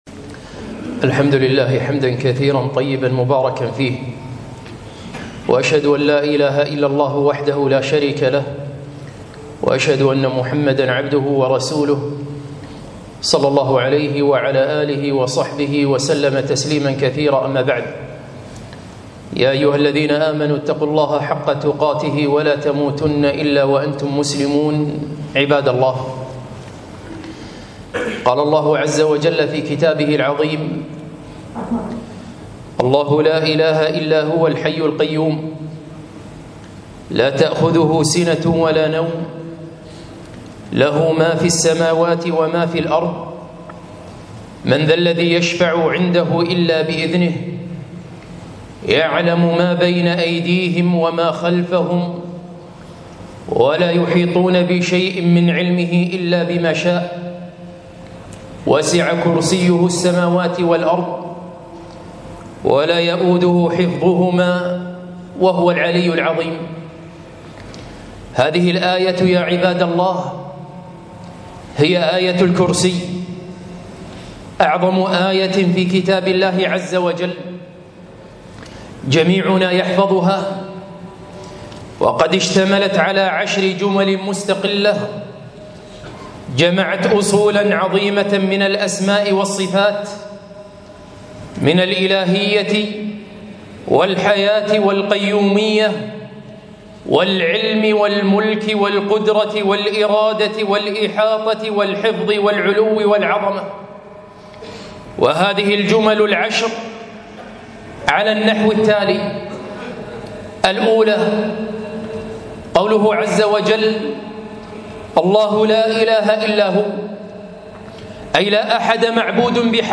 خطبة - آية الكرسي